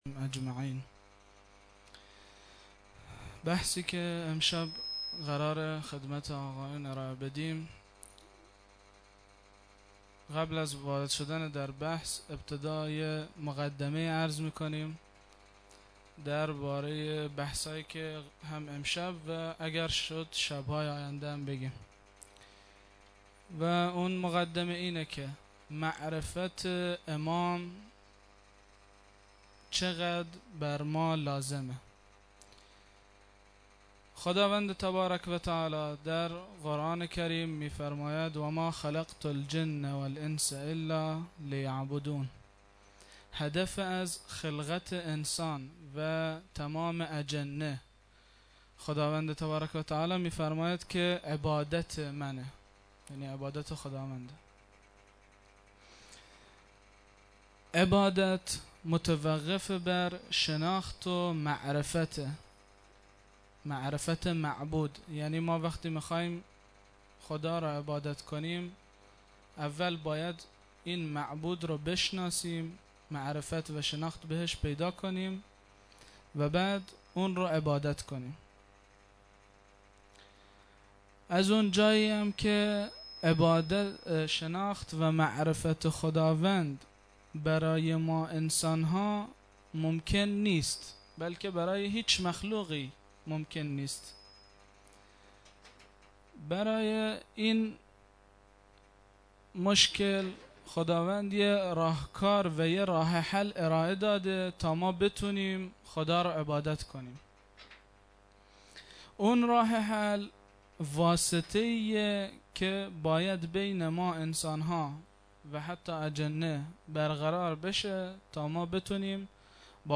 21-ramezan-93-sokhanrani-2.mp3